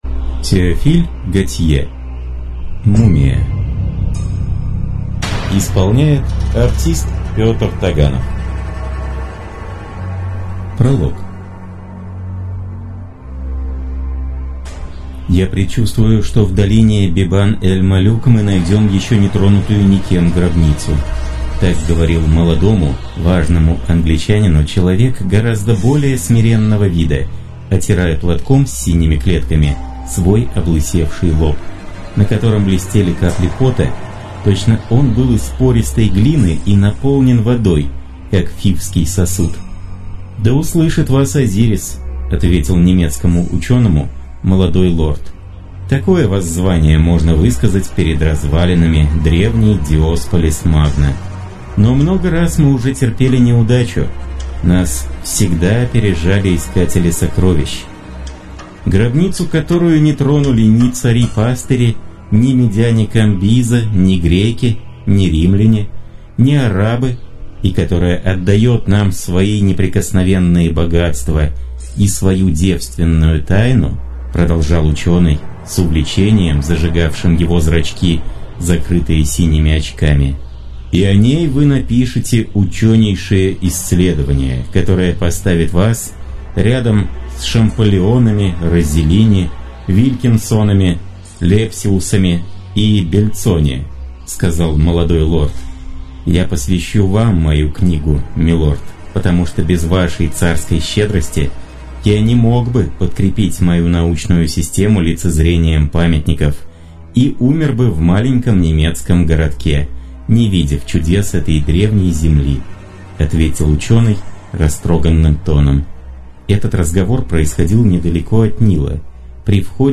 Аудиокнига Мумия | Библиотека аудиокниг